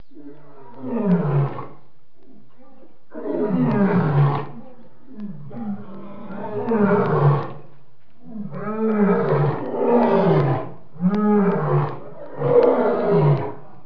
دانلود صدای حیوانات جنگلی 100 از ساعد نیوز با لینک مستقیم و کیفیت بالا
جلوه های صوتی